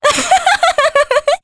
Veronica-Vox_Happy3_jp.wav